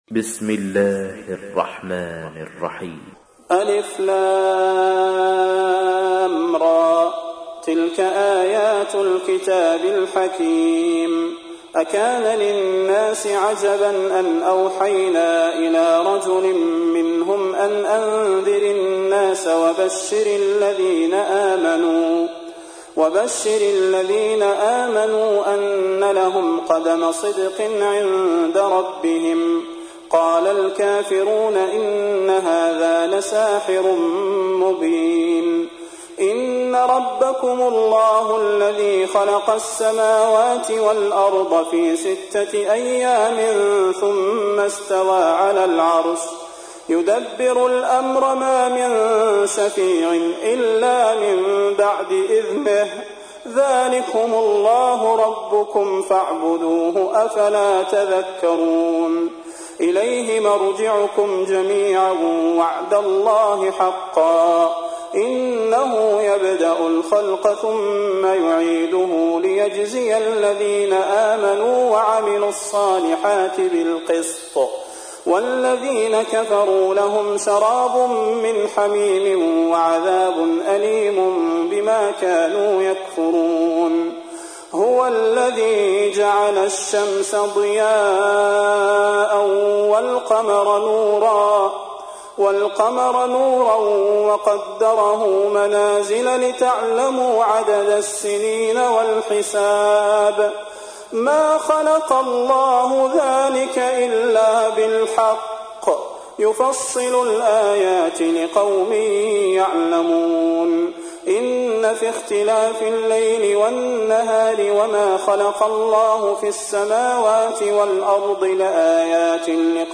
تحميل : 10. سورة يونس / القارئ صلاح البدير / القرآن الكريم / موقع يا حسين